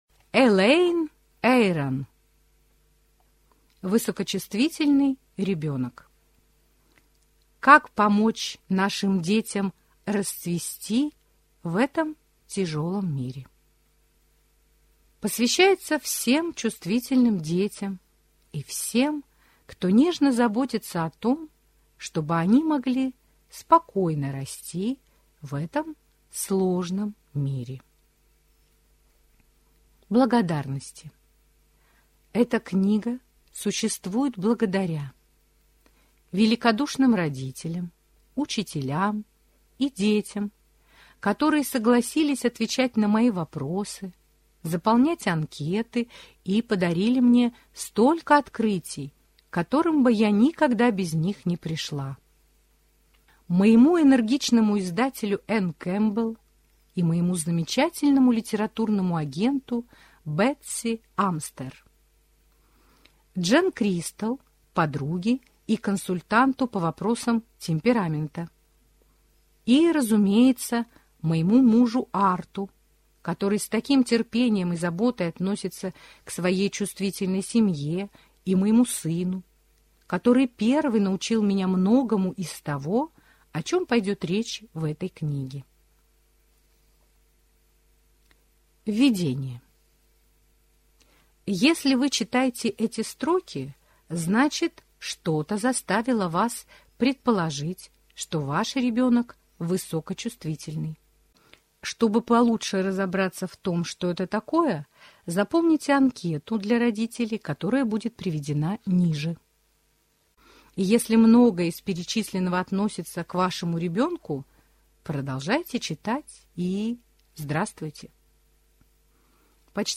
Аудиокнига Высокочувствительный ребенок. Как помочь нашим детям расцвести в этом тяжелом мире | Библиотека аудиокниг